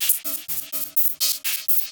RI_ArpegiFex_125-05.wav